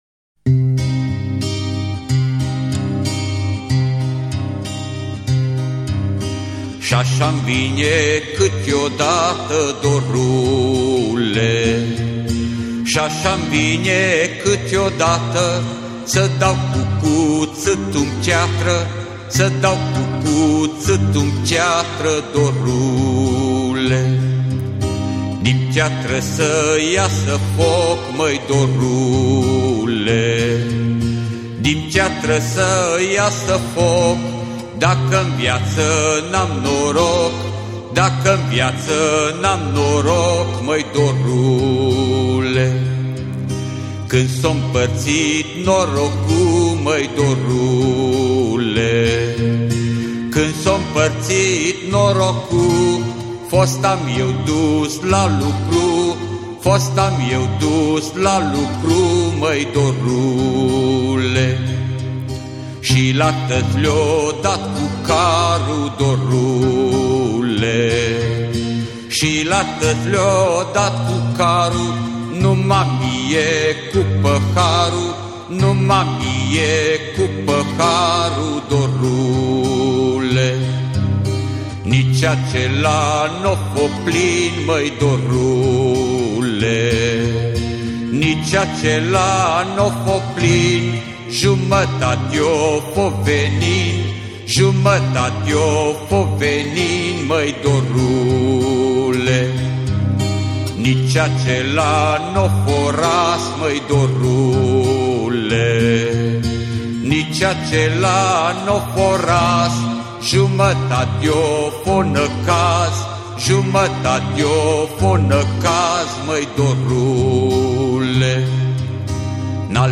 Muzica Unei Generaţii Perene - Aniversarea a 50 de ani de activitate artistică a chitaristului şi cantautorului de muzică folk DUCU BERTZI!
„Când s-o-mpărţit norocu'” (Prel. şi vers. populare, aranj. muz. Ducu Bertzi) de pe albumul „Dor de ducă” (C&P 1997 Intercont Music) interpretat de Ducu Bertzi.